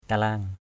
/ka-la:ŋ/ 1.